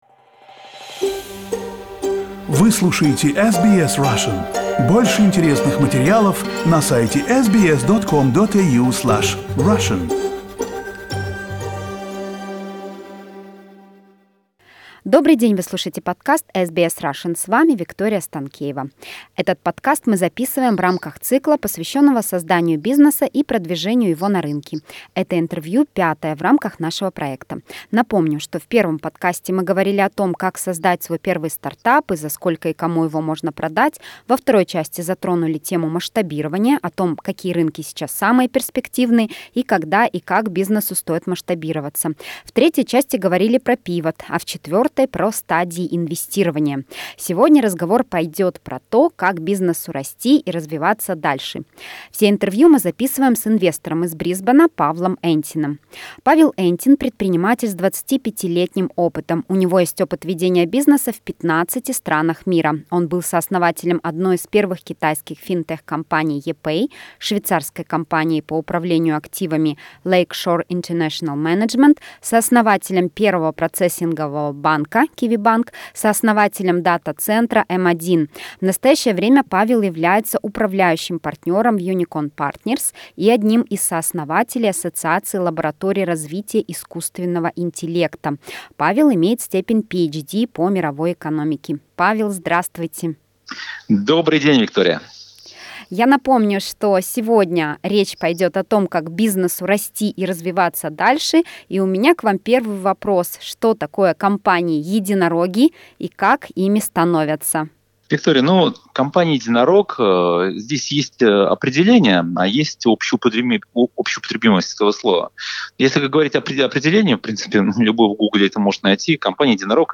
Пятое интервью из цикла с советами для предпринимателей о том, как создавать, выращивать компании и продвигать их на рынки.